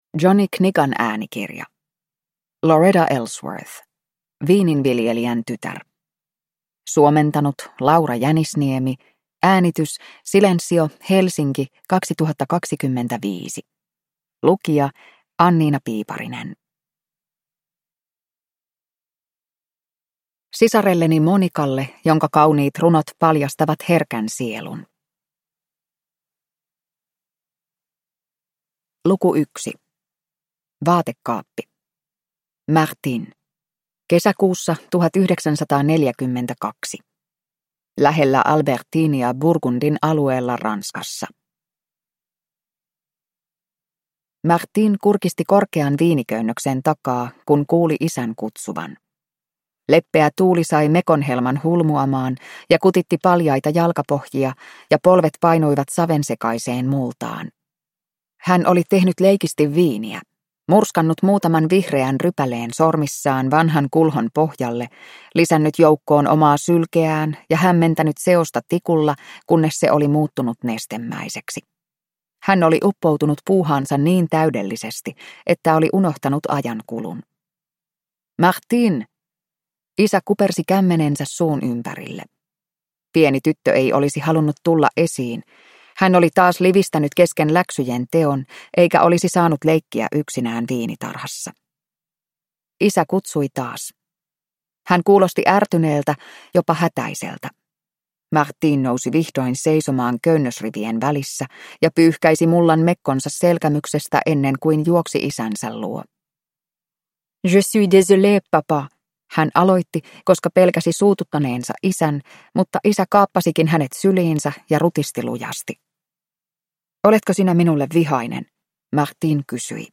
Viininviljelijän tytär – Ljudbok